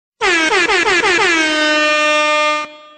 Figure 1. Airhorn in Xcode
Our internal testing has shown that it is very annoying.
dj-airhorn-sound.wav